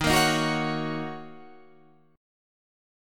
Ebm7 chord